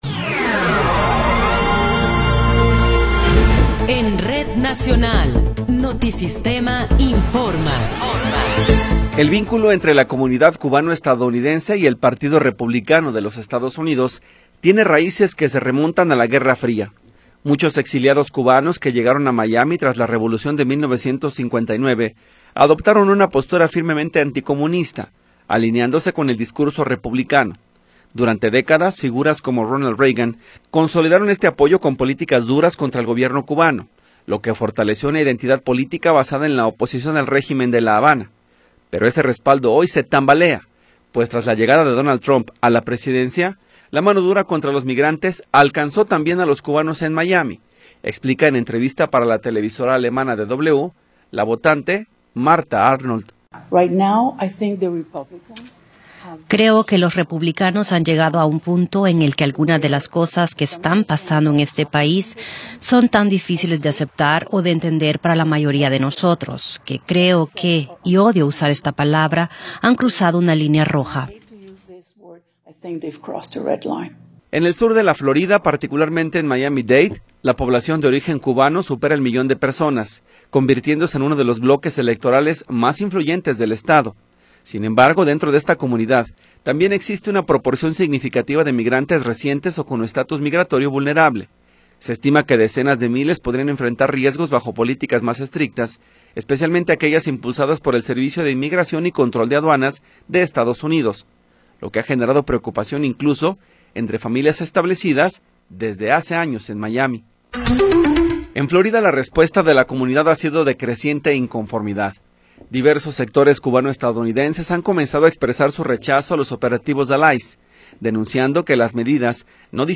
Noticiero 13 hrs. – 19 de Abril de 2026